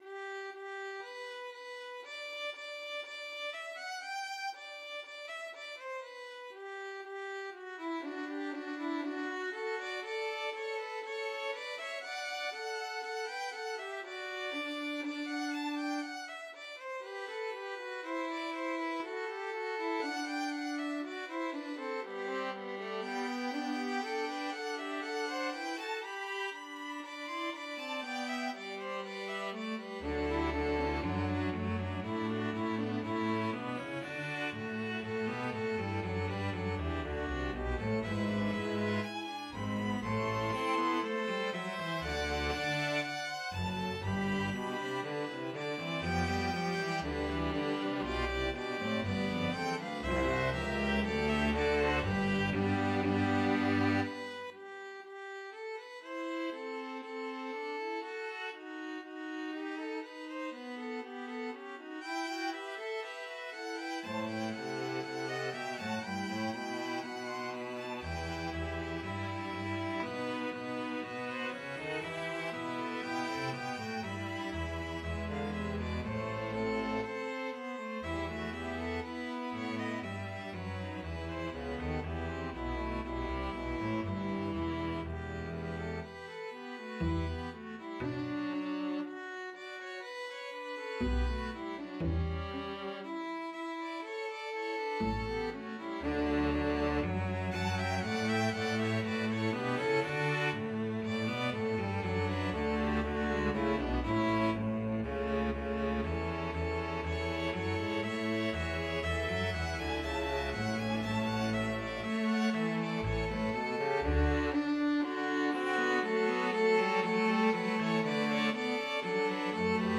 set of parts – intermediate/advanced       (violin 1 and 2, viola/violin 3, cello, double bass)
Cornish-May-Song-Fugue-Ensemble-Score-mp3.mp3